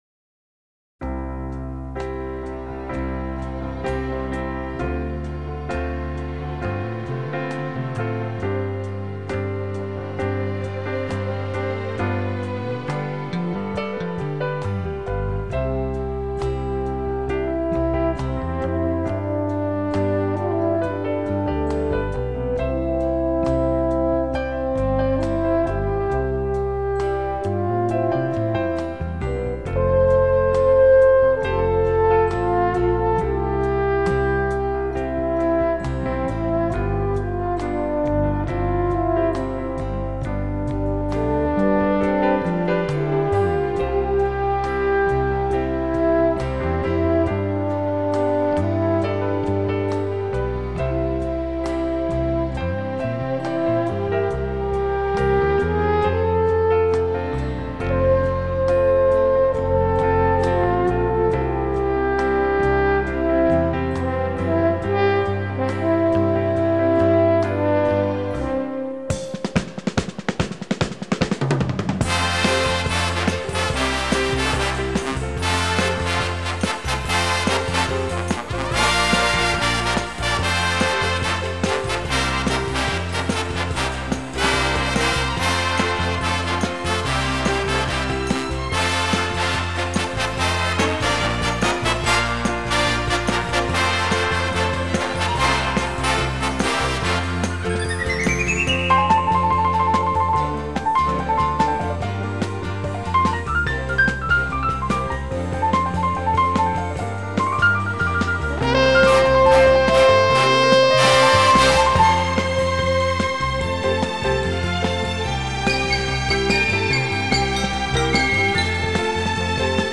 纯音雅乐
分类： 古典音乐、新世纪、纯音雅乐